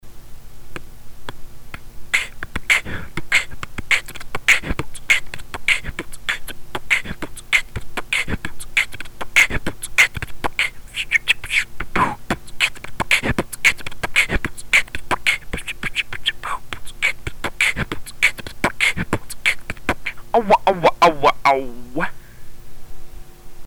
ps: извиняюсь за звук записываю на что есть)
Ну что абсолютно точно: работай над вокал скретчем ^^
только четкость.....погромче делай звуки)
кик, хай-хеты, снеры, и хендклеп...
клеп вообще мощно должен звучать)